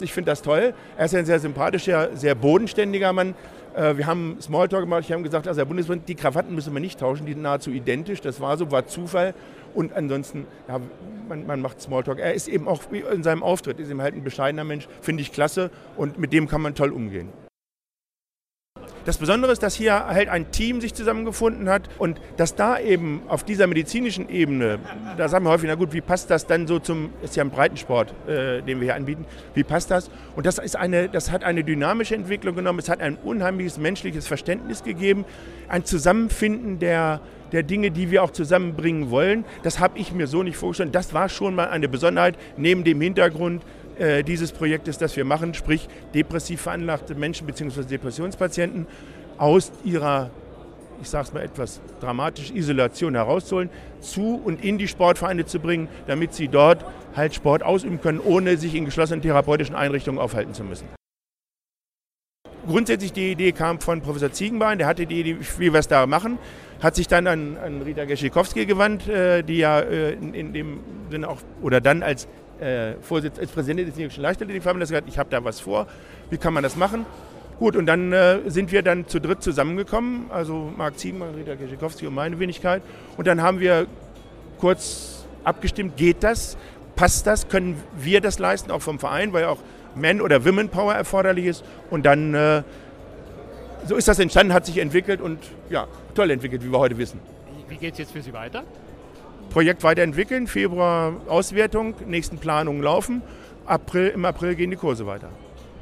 O-Töne / Radiobeiträge, , , ,